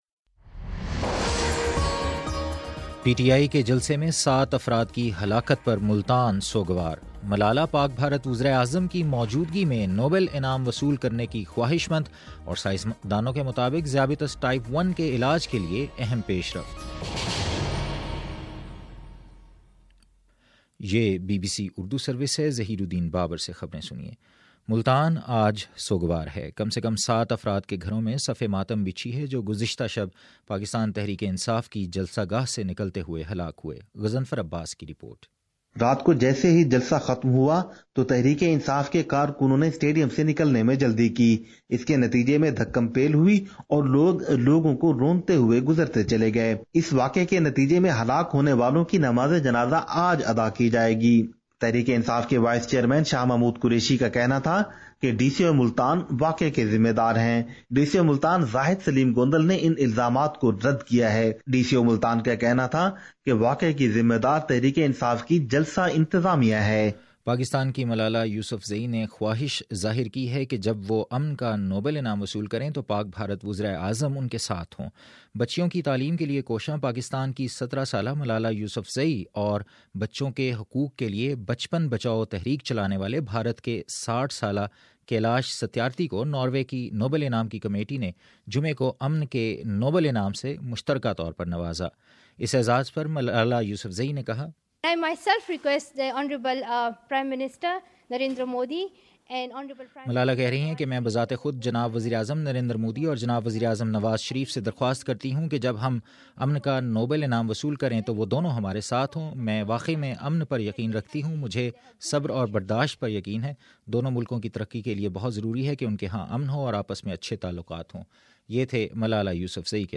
اکتوبر 11 : صبح نو بجے کا نیوز بُلیٹن